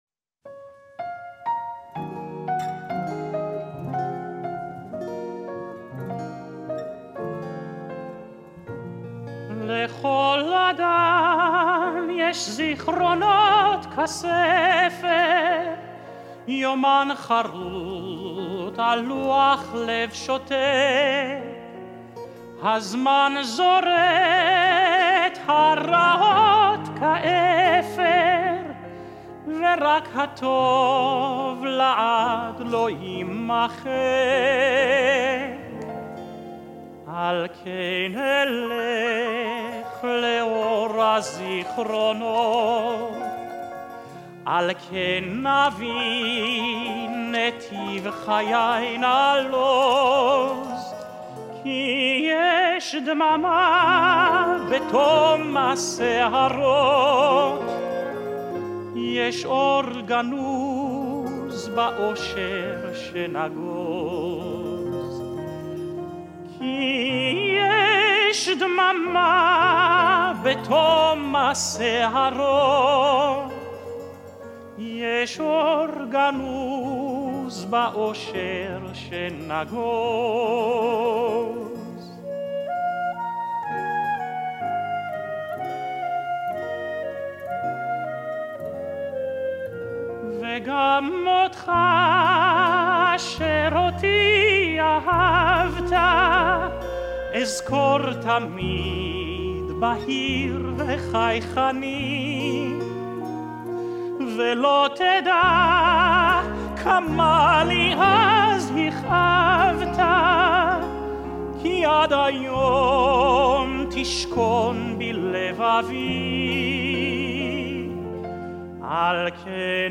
Israeli song
Live from Rosh Hashanah service at Park Avenue Synagogue, 2023